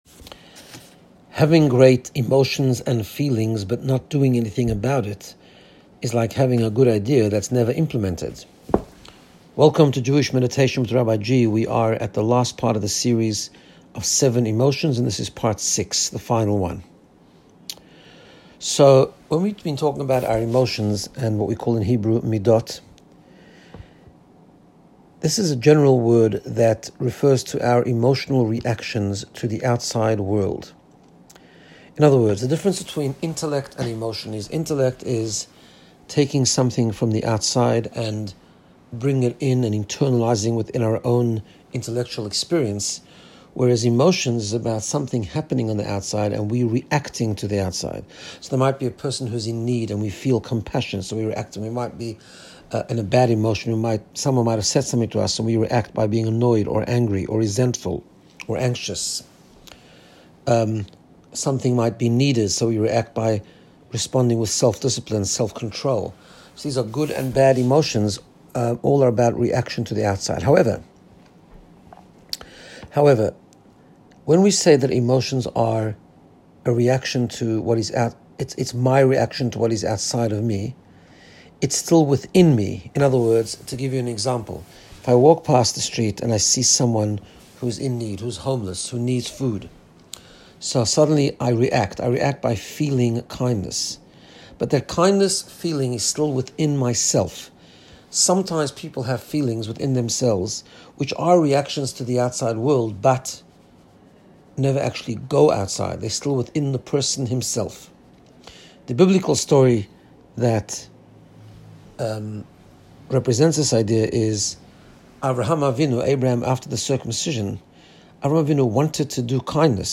Guided Meditation to get you in the space of Personal Growth and Wellbeing. In this episode we learn about Yesod and Malchut - Connection and Leadership. Positive emotions are good but acting on them is the goal.
Meditation-shelach.m4a